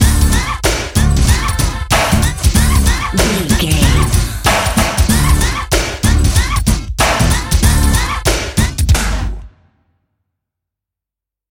Aeolian/Minor
drum machine
synthesiser
90s
Eurodance